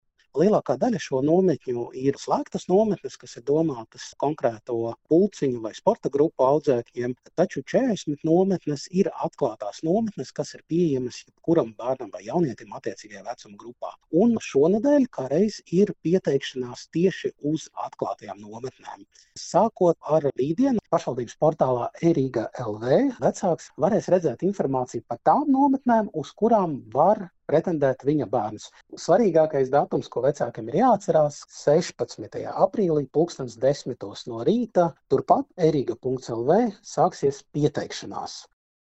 Saruna ar Rīgas domes Izglītības